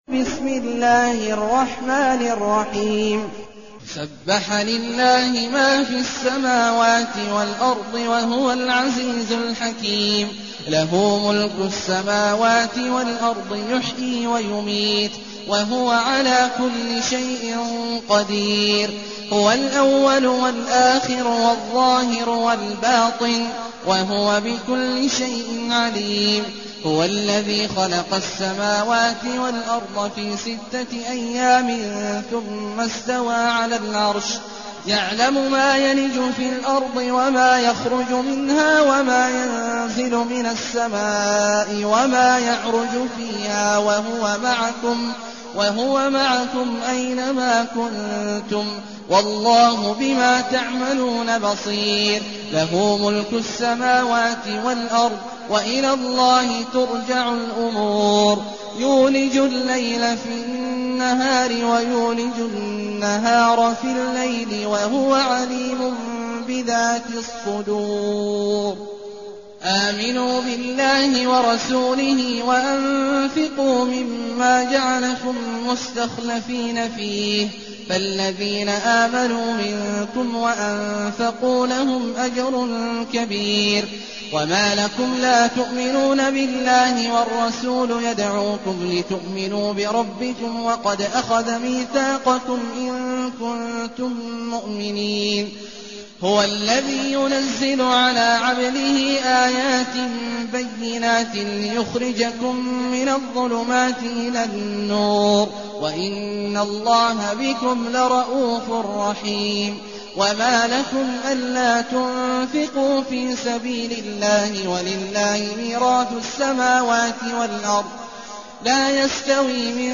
المكان: المسجد الحرام الشيخ: عبد الله عواد الجهني عبد الله عواد الجهني الحديد